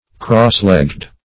Crosslegged \Cross"legged`\ (-l?gd`), a.